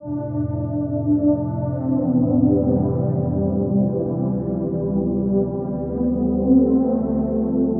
虚无缥缈的深层垫
描述：用于蒸汽波/审美音乐。用Nexus制作。
标签： 123 bpm Ambient Loops Pad Loops 1.31 MB wav Key : F
声道立体声